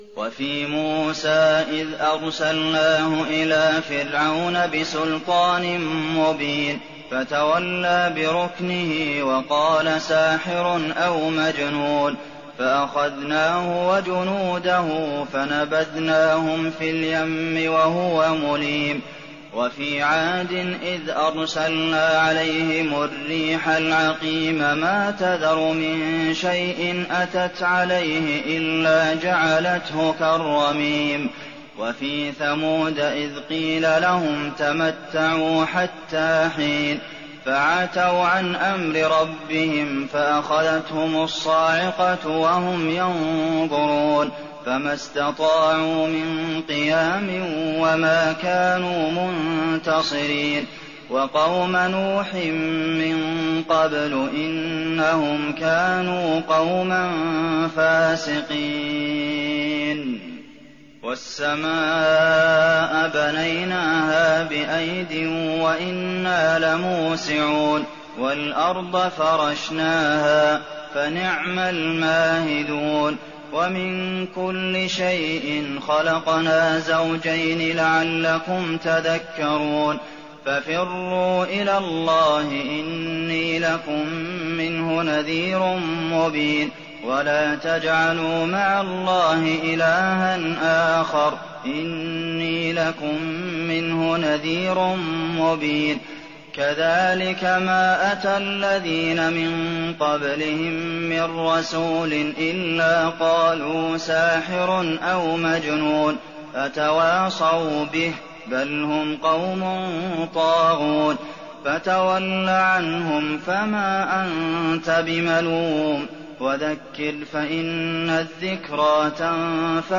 تراويح ليلة 26 رمضان 1419هـ من سورة الذاريات (38-60) الى سورة القمر Taraweeh 26th night Ramadan 1419H from Surah Adh-Dhaariyat to Al-Qamar > تراويح الحرم النبوي عام 1419 🕌 > التراويح - تلاوات الحرمين